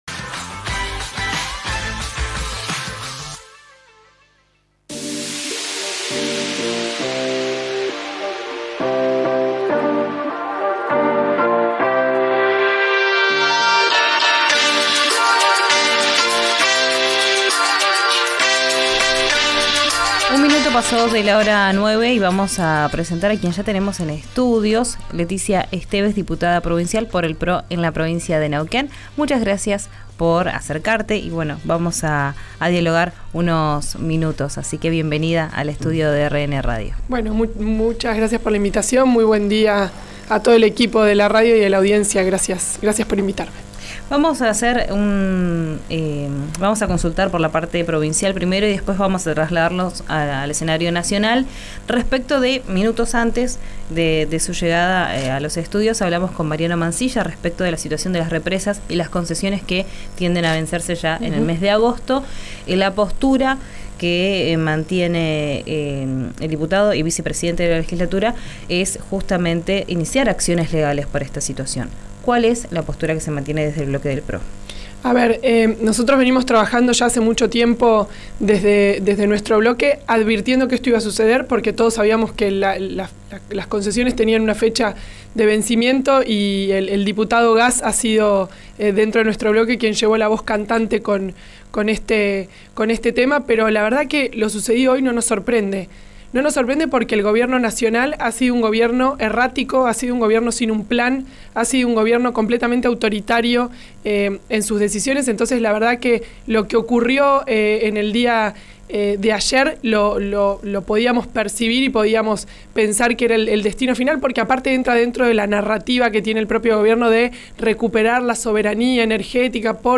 La diputada dialogó hoy con «Vos Al Aire» por RÍO NEGRO RADIO y se refirió también a las negociaciones que se vienen haciendo en Buenos Aires para la incorporación de José Luis Espert y Juan Schiaretti al espacio político.